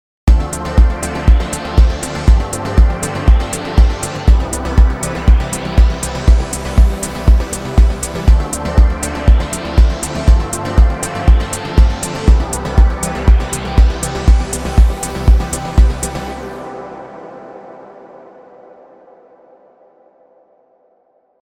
次に並列（センド/リターン）でKR-Spaceをかけてみます。
今回は“Trembling-Waterfalls”を選択してみました。
エフェクトが入るとこんな感じです。
KR-Spaceがかかっているパートの音が煌びやかな感じになって、音が広がりましたね。いきなりゴージャスな雰囲気に。